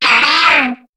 Cri de Lombre dans Pokémon HOME.